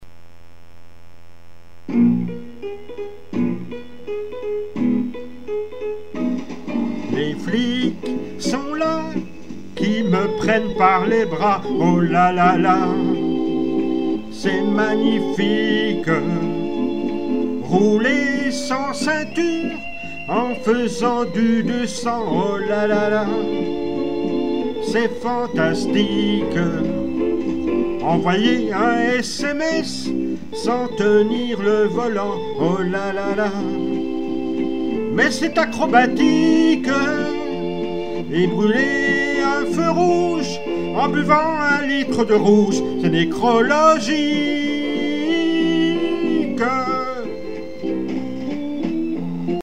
Chantez avec moi...